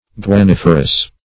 Guaniferous \Gua*nif"er*ous\, a.